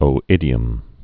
(ō-ĭdē-əm)